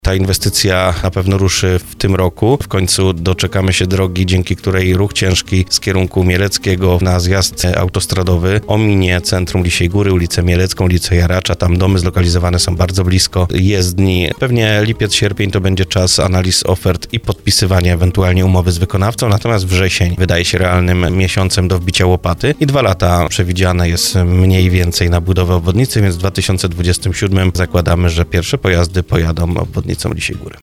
O harmonogramie inwestycji mówił wójt gminy Lisia Góra Arkadiusz Mikuła w rozmowie Słowo za Słowo.